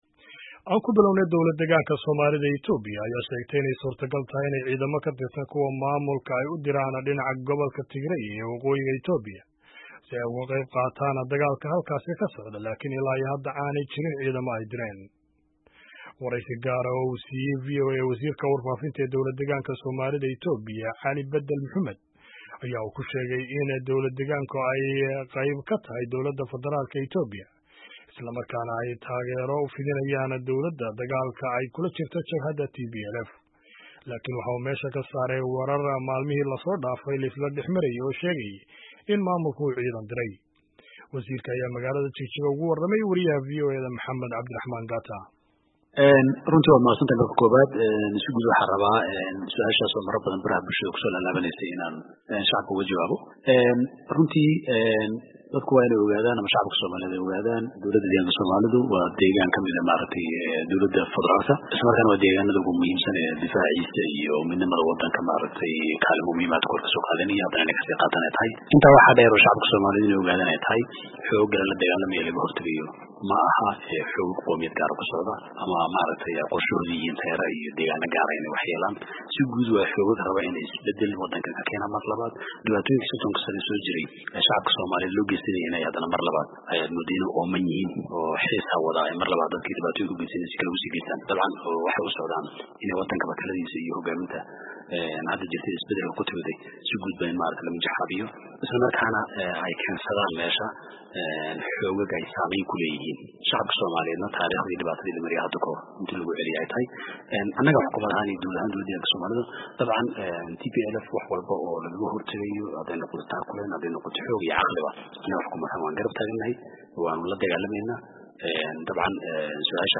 Wareysi gaar ah oo uu siiyay VOA, Wasiirka Warfaafinta ee Dawlad Deegaanka Soomaalida ee Ethiopia Cali Badal Muxumed ayuu ku sheegay in Dowlad Deegaanka ay qeyb ka tahay Dowladda Federalka ee Ethiopia isla markaana ay taageero u fidinayaan dowladda dagaalka ay kula jirto Jabhadda TPL
Dhageyso wareysiga Wasiirka Warfaafinta Ismaamulka Soomaalida